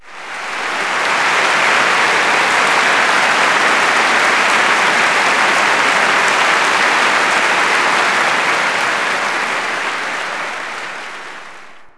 clap_046.wav